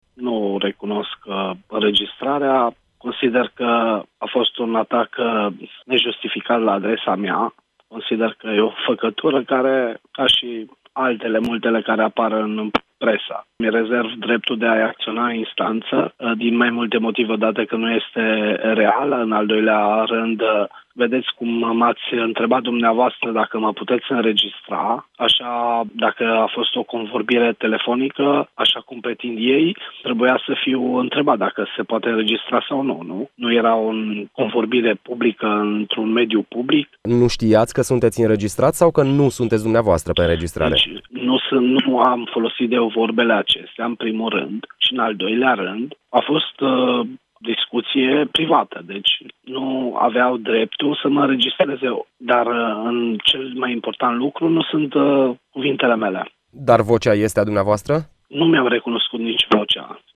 Contactat de Europa FM, vicepreședintele Consiliului Județean Maramureș se apără și spune că înregistrarea e, cităm, ”o făcătură”.